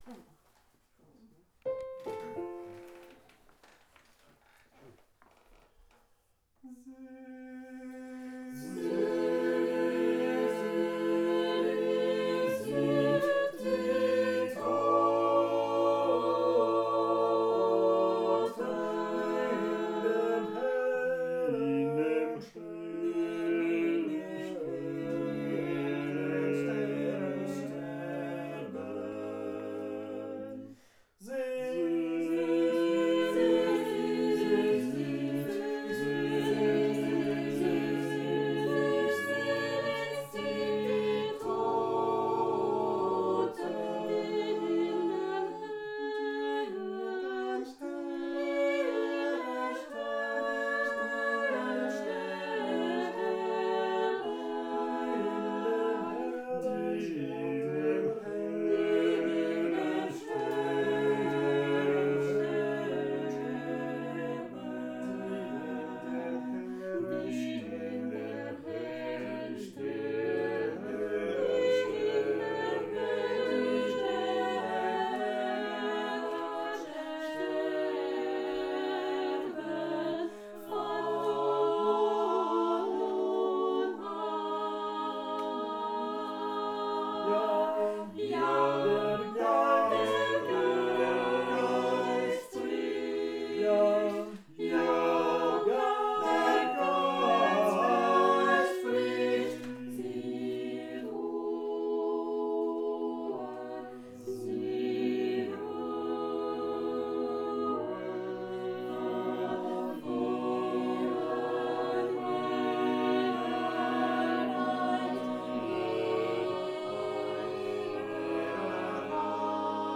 Ce motet reprend un passage célèbre de la Bible de Martin Luther (Apocalypse 14, 13-15) : « Heureux dès à présent ceux qui sont morts dans le Seigneur ; oui, dit l’Esprit, qu’ils se reposent de leurs travaux, et leurs œuvres les suivront. » Malgré un tel contexte, cette pièce musicale n’est pas triste mais au contraire pleine d’espérance et d’entrain.